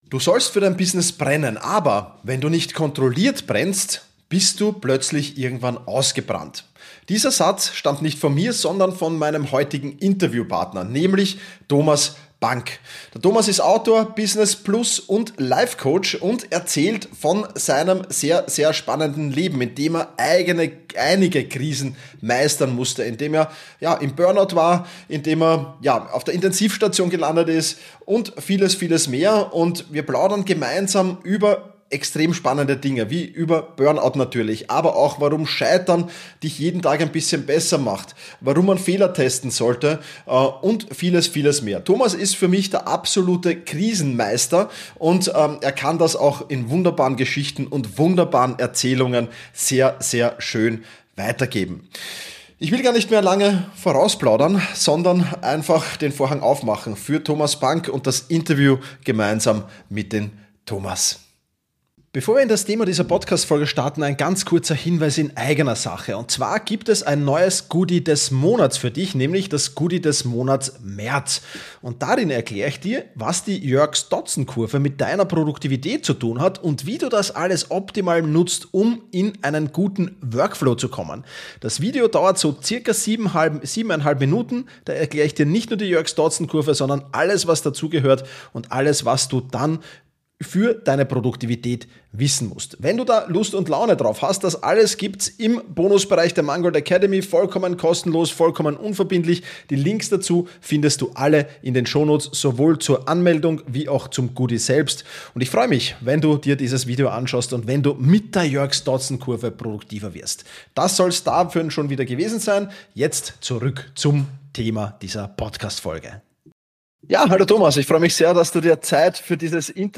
Viel Spaß bei diesem inspirierenden Gespräch!